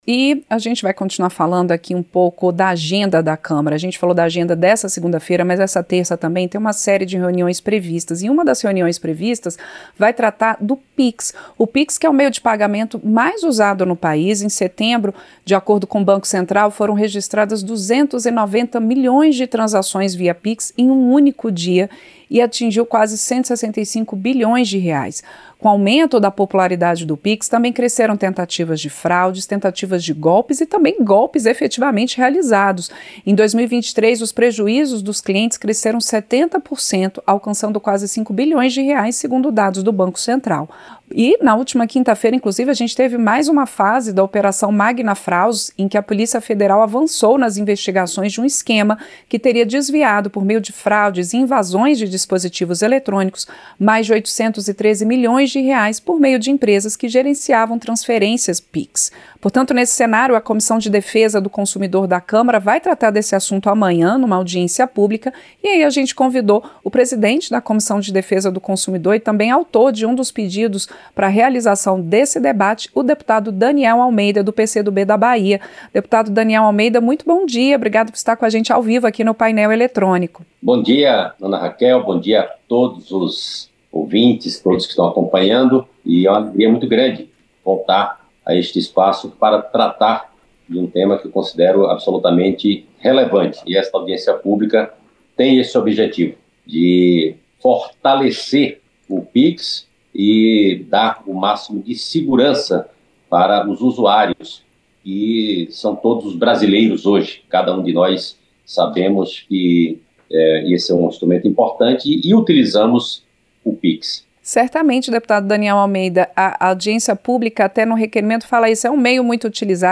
Entrevista - Dep. Daniel Almeida (PCdo-BA)